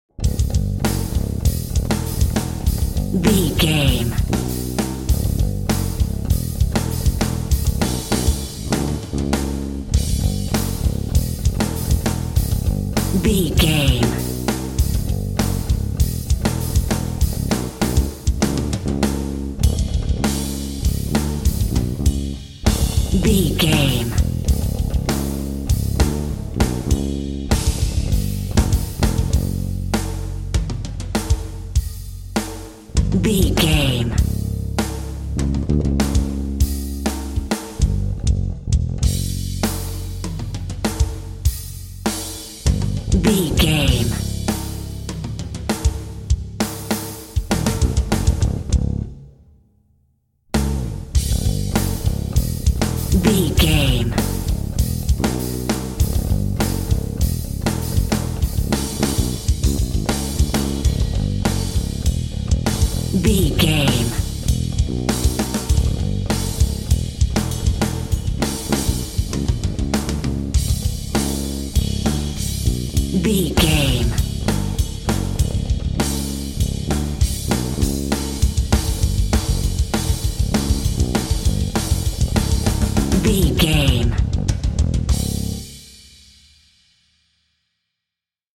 Ionian/Major
C♯
Fast
guitar
drums
ominous
bass guitar
electric guitar
energetic